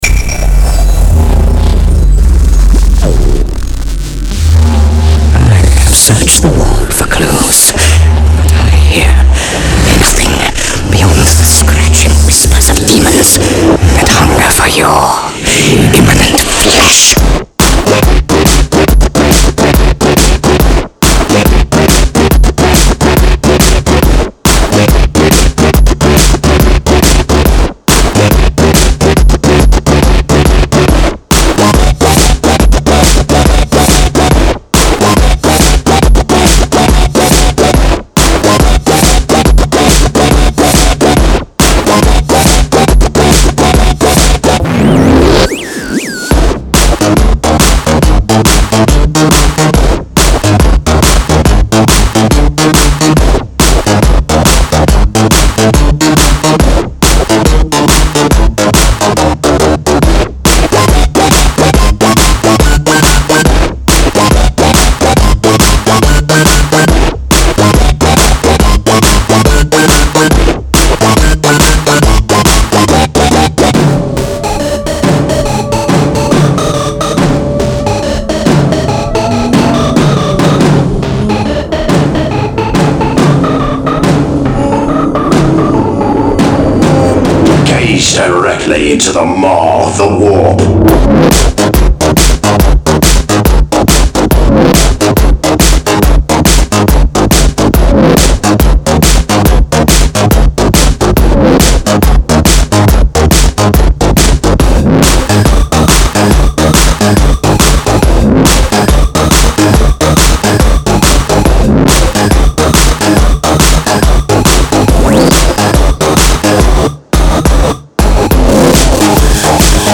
drumstep WIP